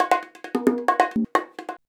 133BONG01.wav